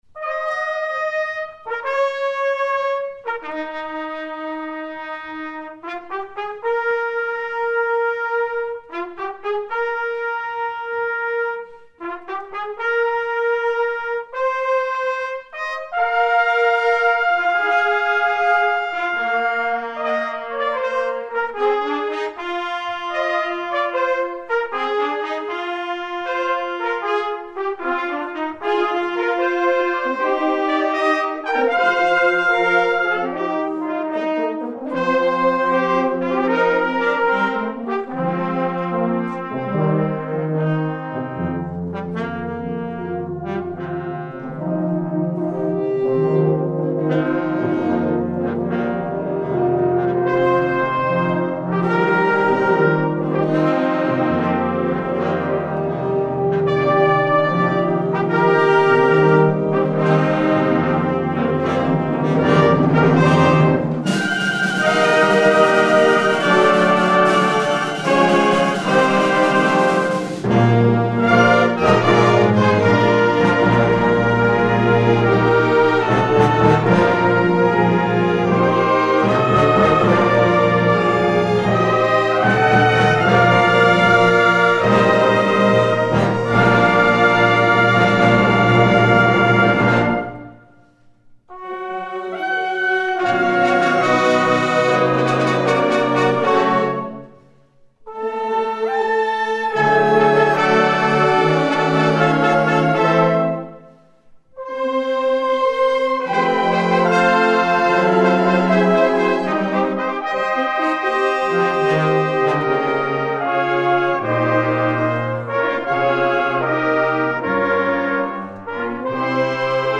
Catégorie Harmonie/Fanfare/Brass-band
Sous-catégorie Musique festive, fanfares, hymnes
Instrumentation Ha (orchestre d'harmonie)
klingen helle, schmetternde Fanfaren